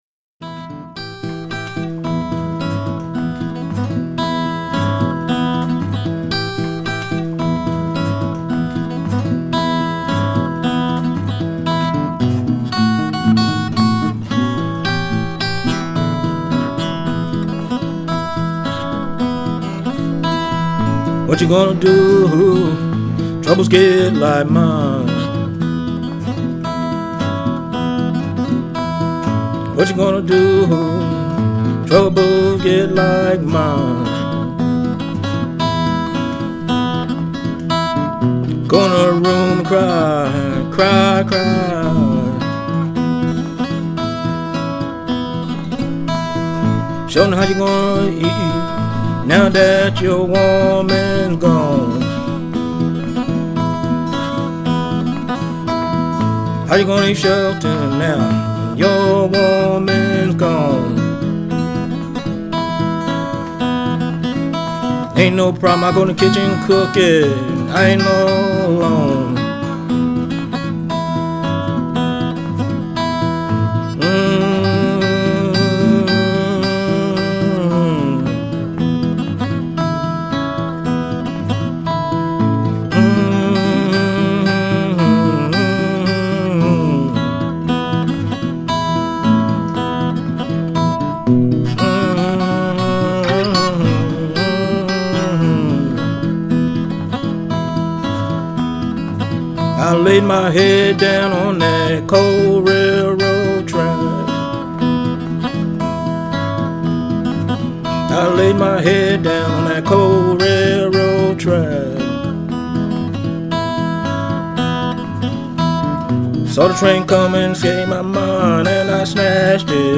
Piedmont blues guitarist
solo acoustic tunes
the collection is raw and hypnotic
fingerstyle guitar work and gritty vocals